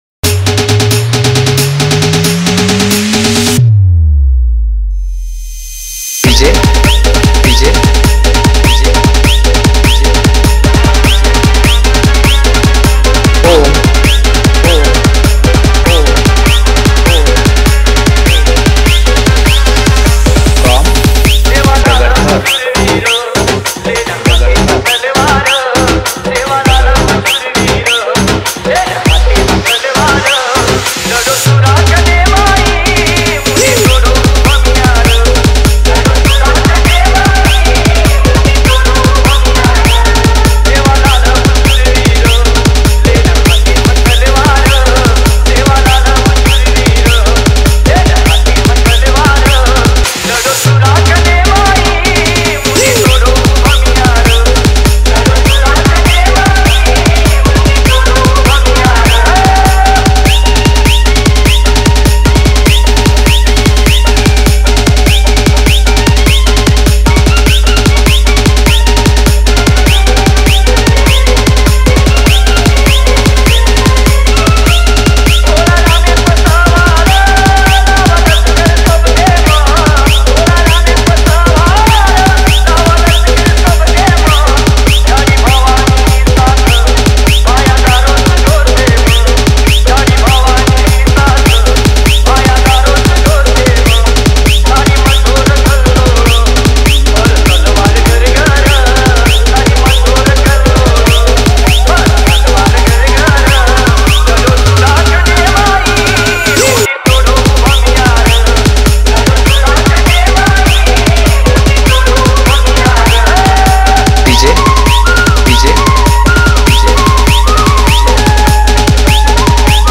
BANJARA DJ SONG 2